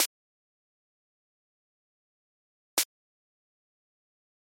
snare.mp3